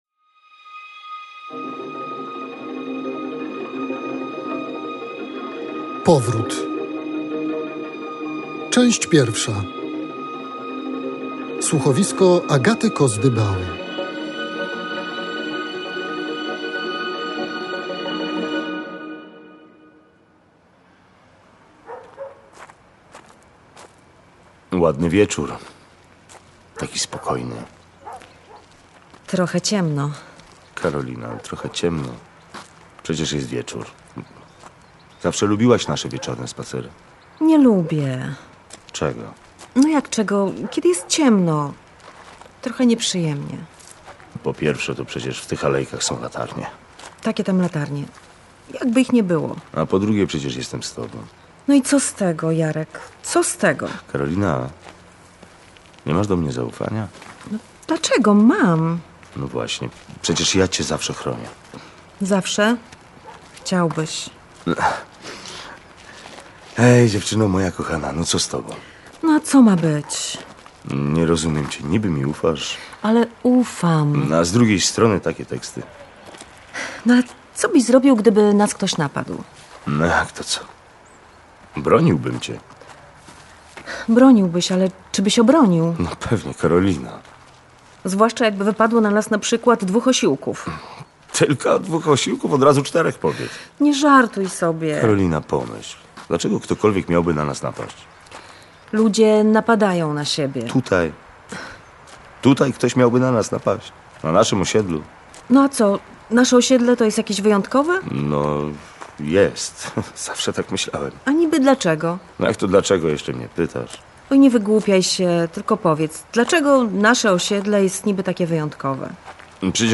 „Powrót” to kolejne kryminalne słuchowisko Radia Lublin.